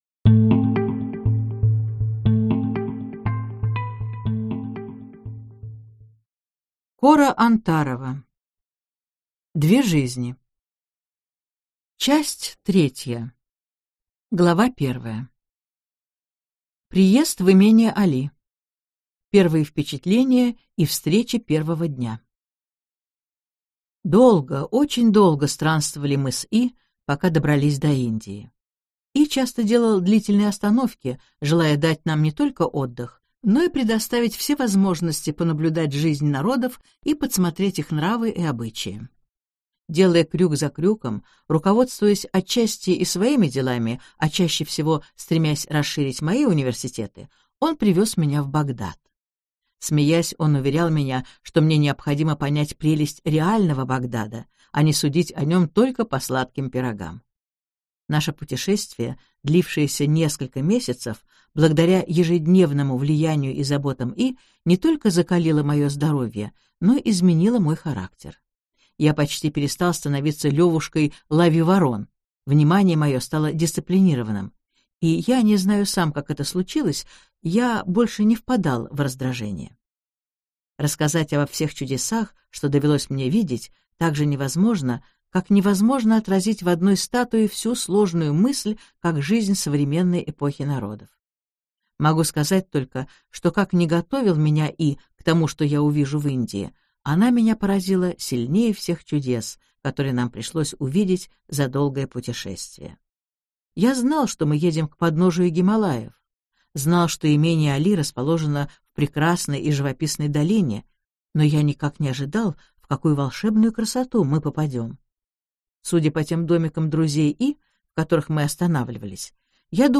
Аудиокнига Две жизни: III часть, в обновленной редакции | Библиотека аудиокниг